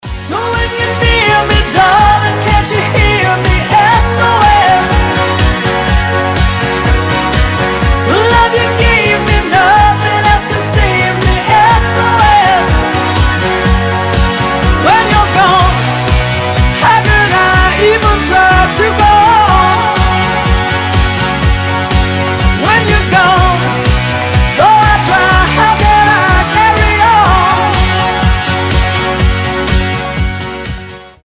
• Качество: 320, Stereo
поп
гитара
громкие
зажигательные
Dance Pop